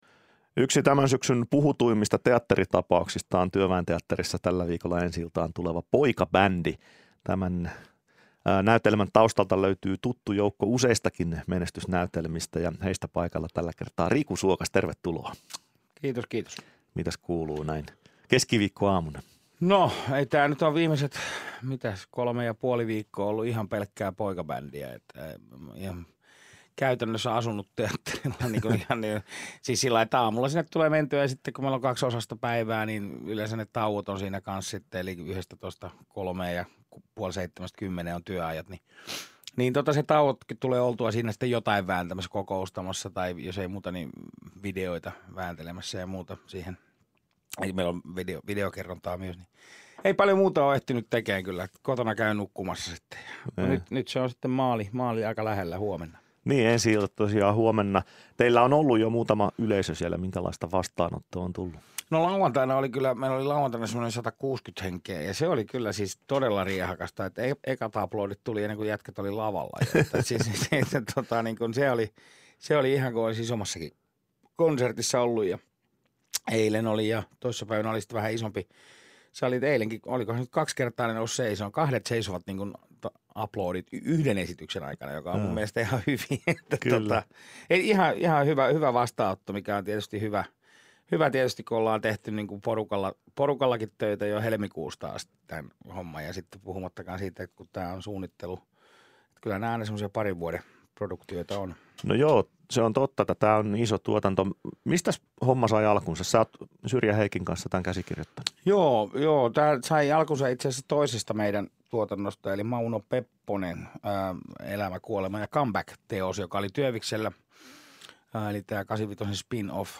Päivän vieras